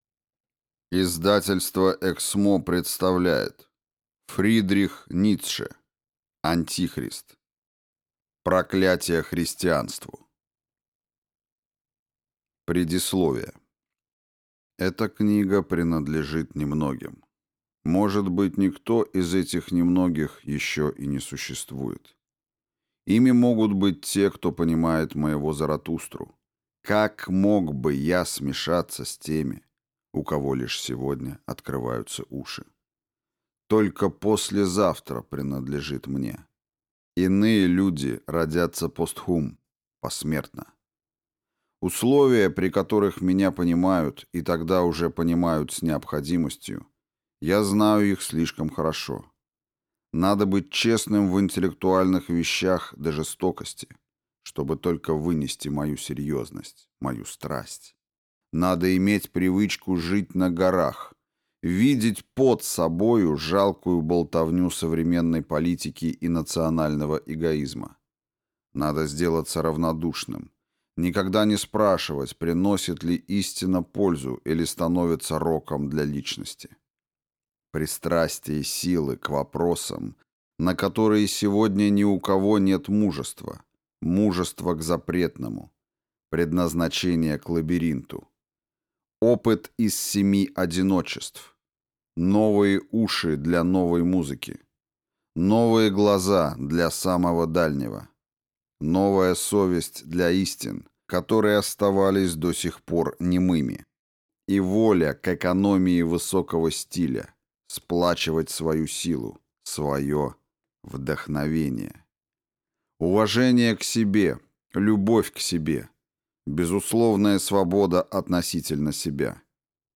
Аудиокнига Антихрист | Библиотека аудиокниг